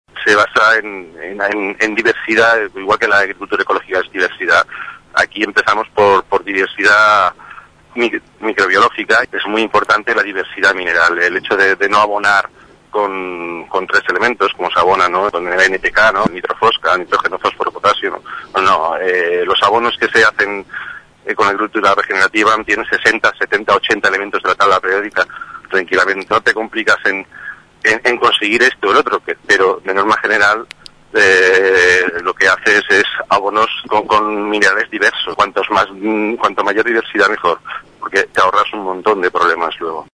L’agricultura regenerativa és un nou paradigma agrícola nascut a Austràlia que potencia els processos de la pròpia natura per regenerar-se a si mateixa reduint les despeses, incrementant la productivitat i fomentant la cohesió i la cooperació entre les economies locals. Ho explicava en declaracions a Ràdio Tordera